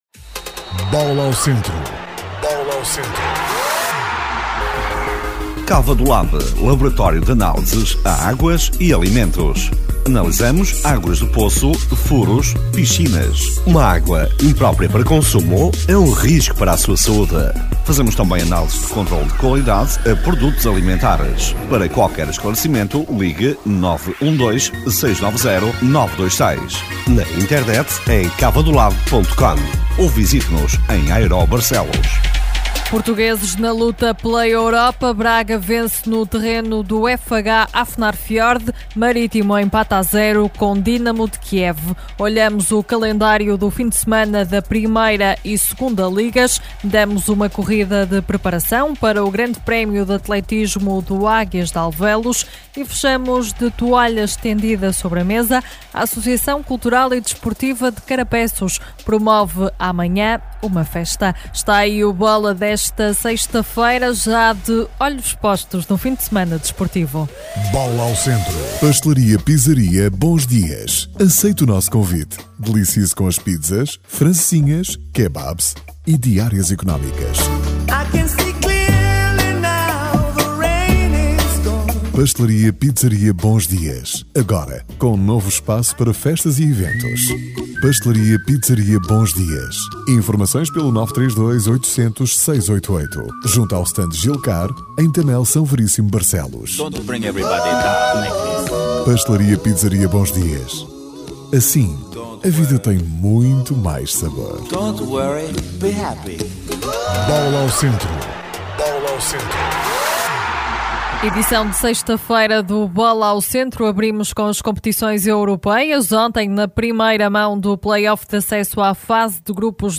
Noticiário de Desporto, emitido durante a semana, às 7h00, 10h00, 13h00, 17h00 e 20h00.